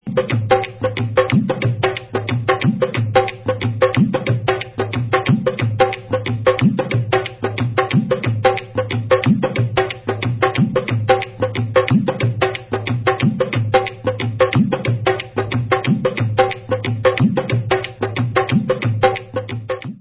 Ladies Sangeet